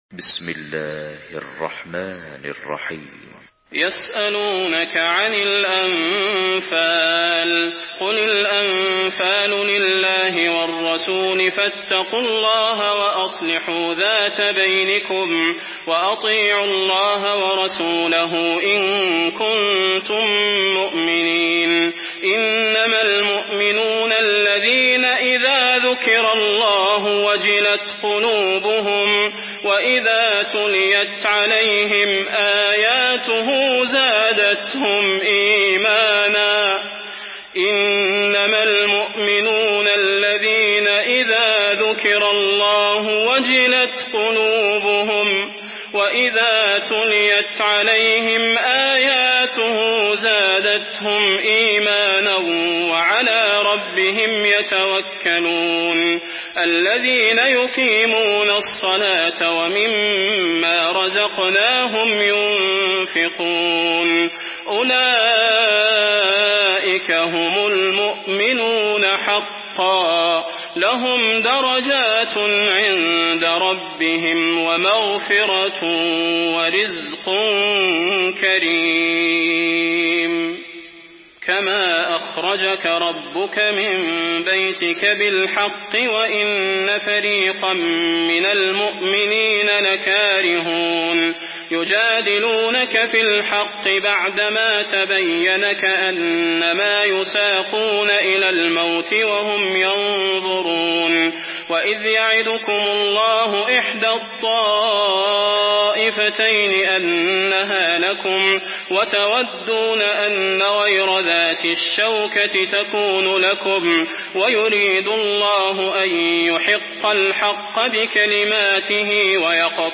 سورة الأنفال MP3 بصوت صلاح البدير برواية حفص
مرتل حفص عن عاصم